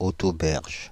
Ääntäminen
Synonyymit quai Ääntäminen France (Île-de-France): IPA: /o.to.bɛʁʒ/ Haettu sana löytyi näillä lähdekielillä: ranska Käännöksiä ei löytynyt valitulle kohdekielelle.